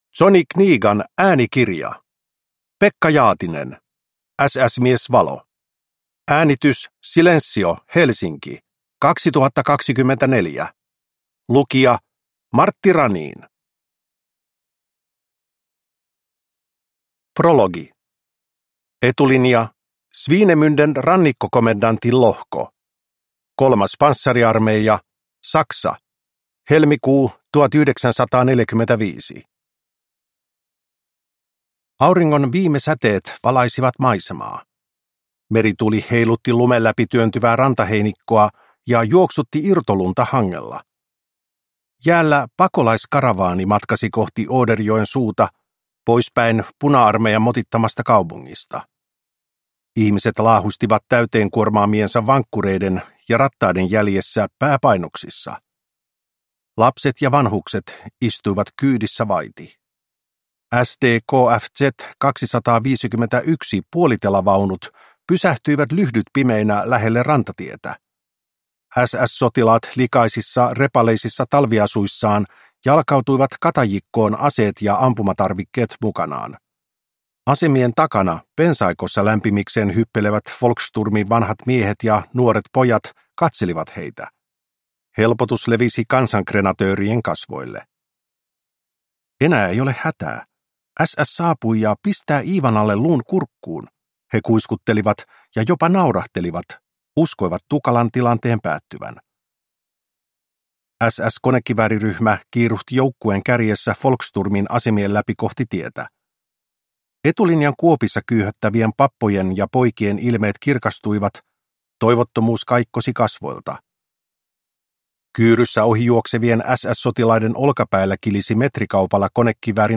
SS-mies Valo – Ljudbok